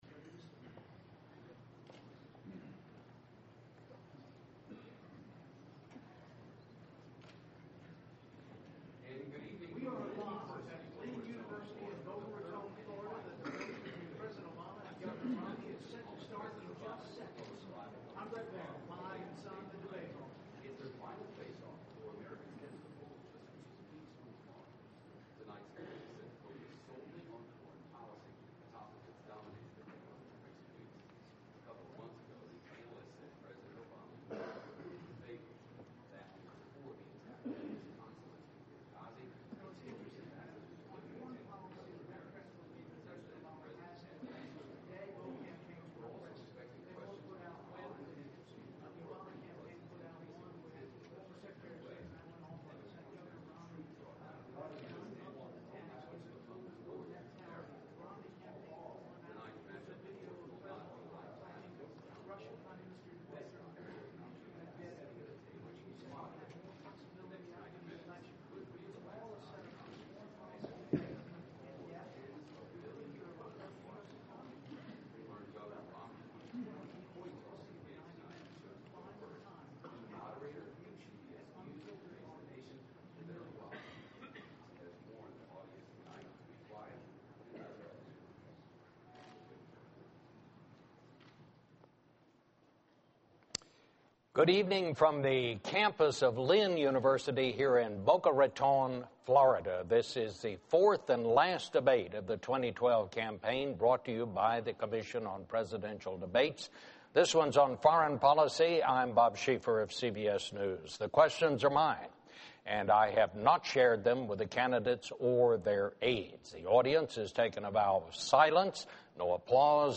President Obama Vs. Governor Romney - Final Debate - English - full